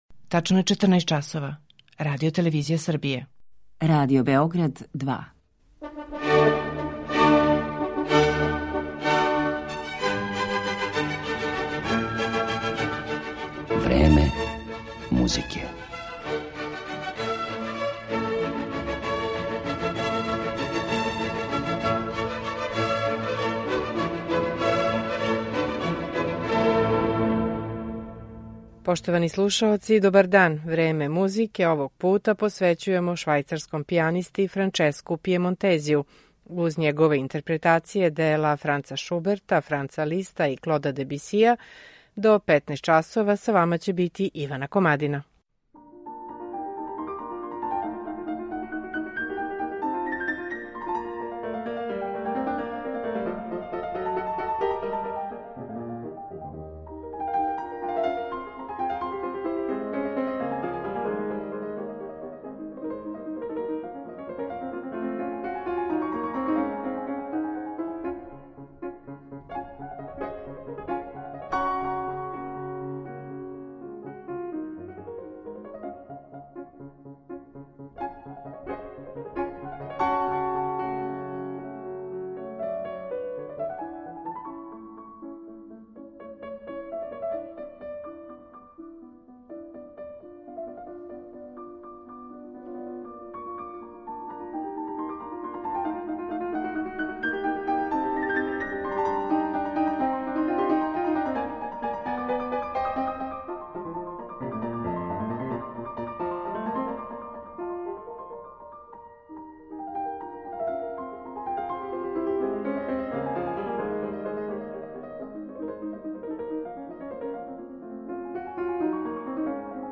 Франческо Пијемонтези, клавир
Не посеже за великим гестовима, радикалним изменама темпа, агогике, динамике. Његов приступ партитури и клавијатури је дискретан, лирски, са деликатним тушеом, усмерен ка истраживању колористичког богатства инструмента.